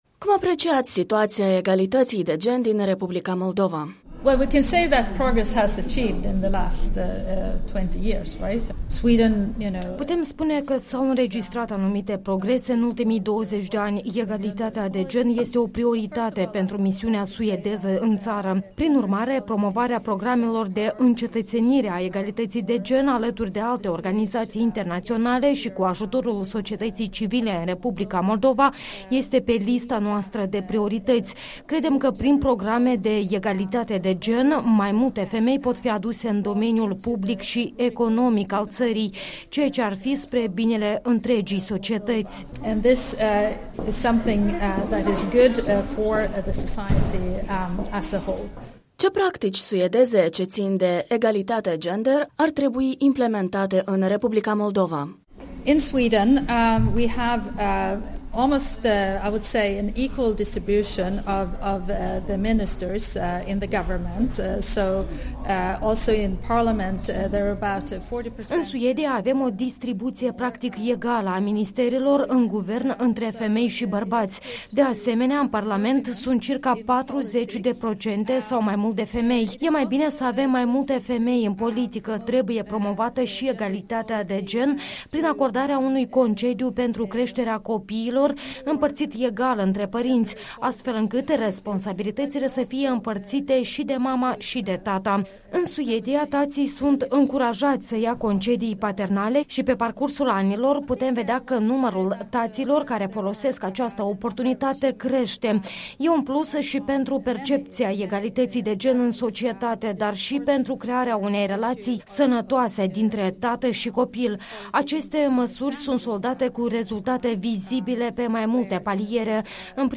Interviu cu ambasadoarea Suediei în Republica Moldova, Signe Burgstaller